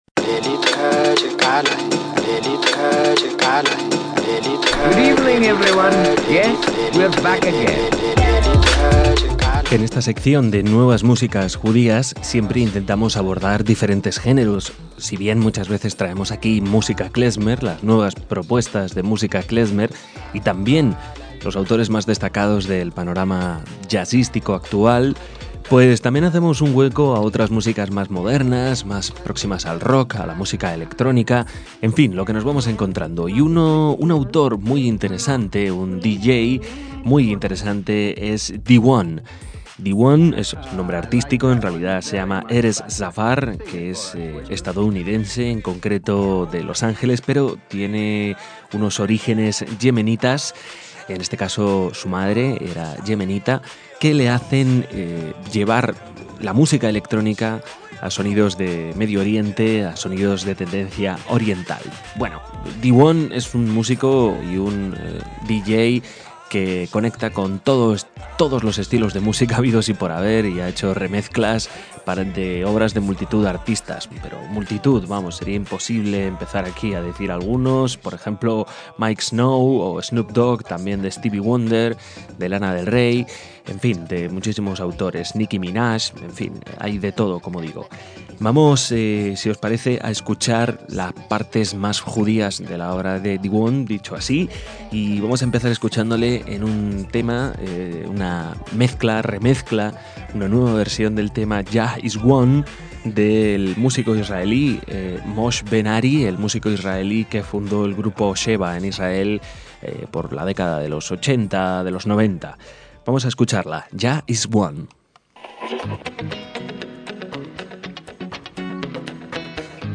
productor y DJ de músicas del mundo